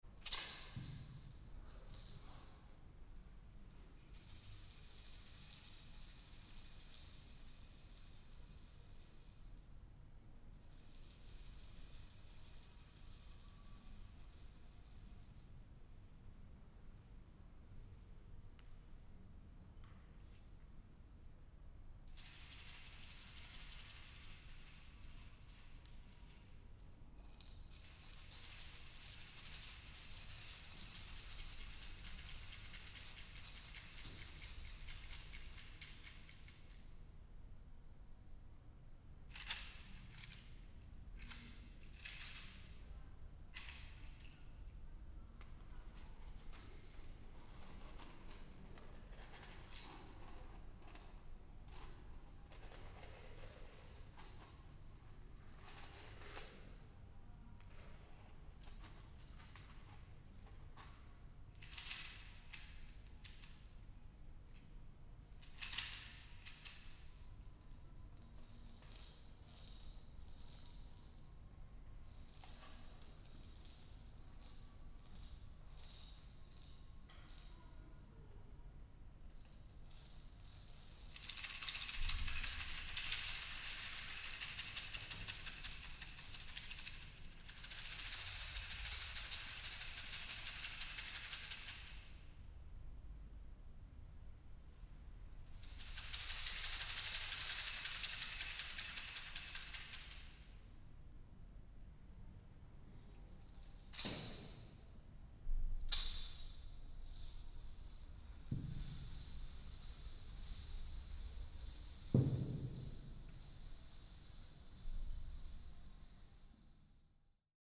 audio recording & audio mix (concert)